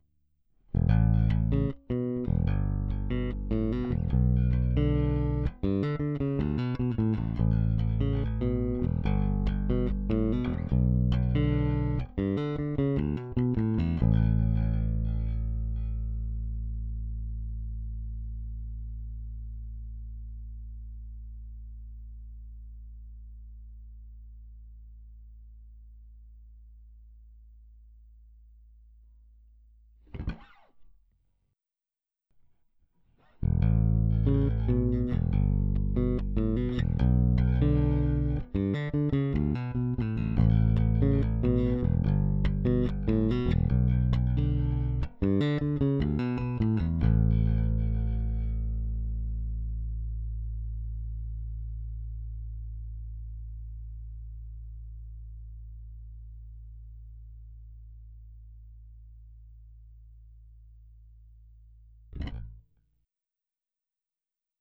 Ihr könnt ja selber mal schauen, ob ihr einen Unterschied wahrnehmt und einen Tip abgeben, welche Brücke welche ist.